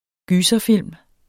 Udtale [ ˈgyːsʌˌfilˀm ]